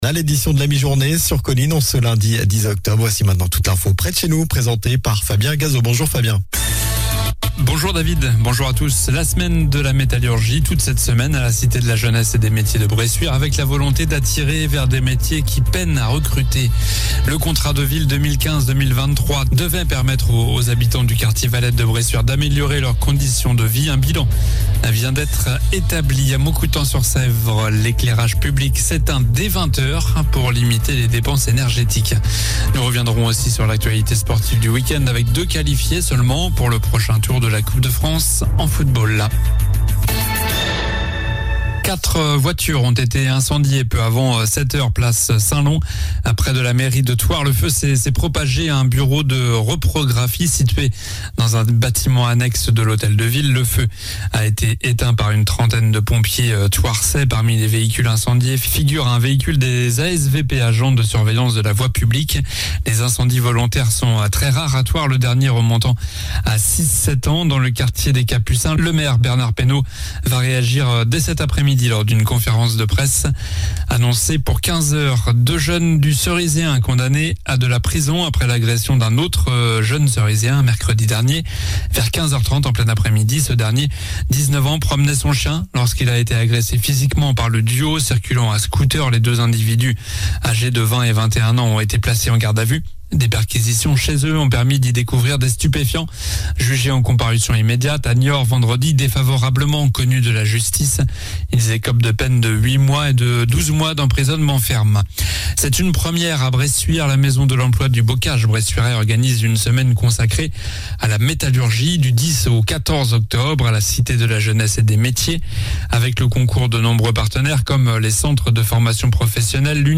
Journal du lundi 10 octobre (midi)